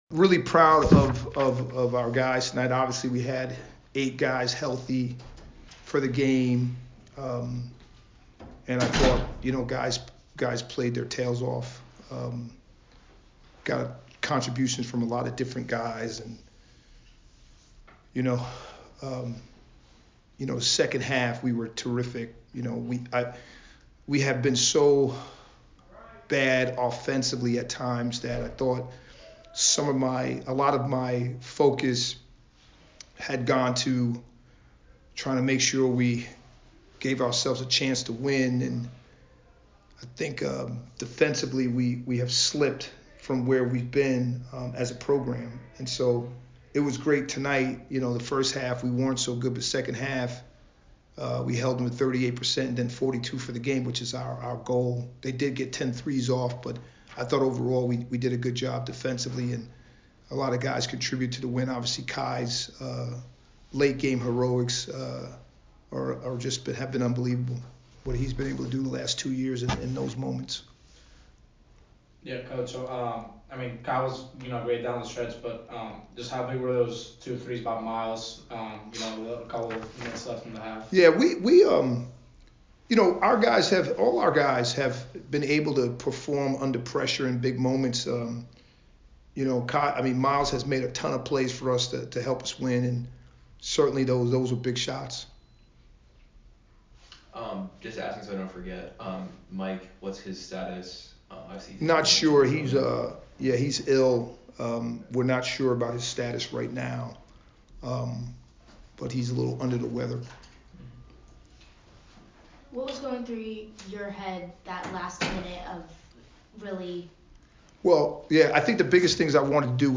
Loyola Maryland Postgame Interview